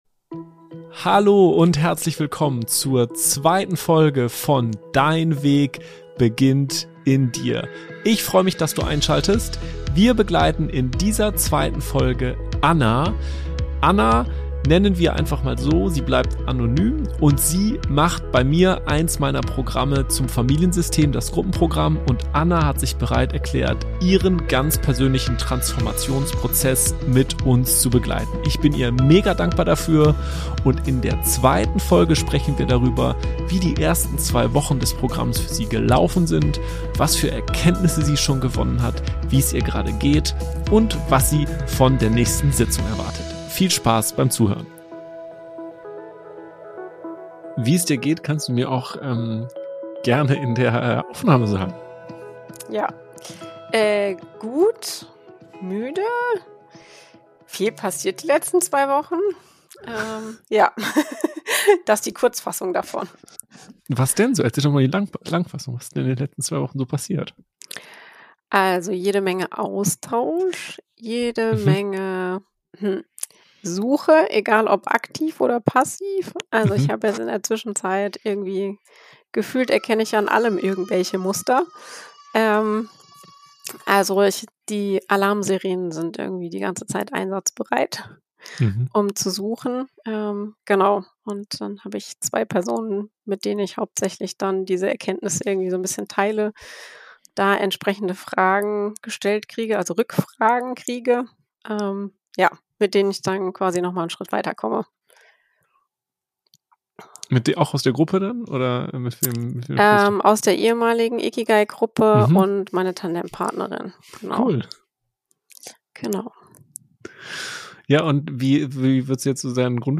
Ein Gespräch über Mut, Erinnerung, Nähe und den langen Weg zurück zu sich selbst.